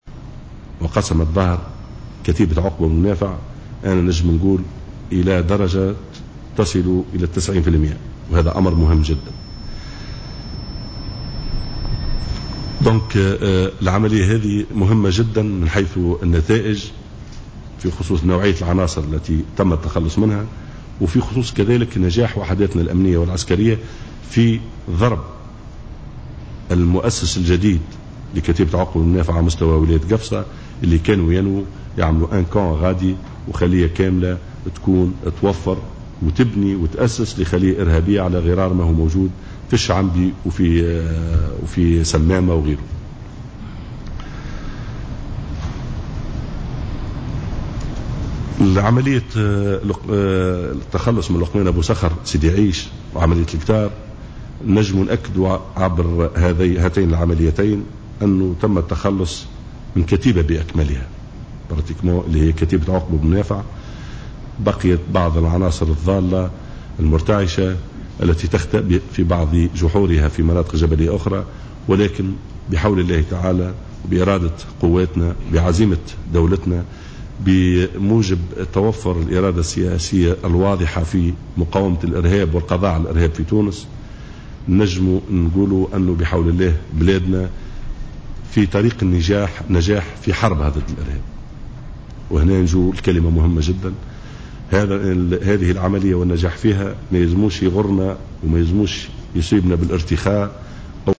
أكد وزير الداخلية محمد الناجم الغرسلي في ندوة صحفية عقدتها الوزارة مساء اليوم الأحد أن عملية قفصة الامنية التي أسفرت عن مقتل 5 عناصر ارهابية تعد الأخطر في تونس تاتي امتدادا لعملية سيدي عيش التي تم خلالها القضاء على الإرهابي لقمان ابو صخر .